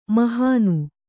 ♪ mahānu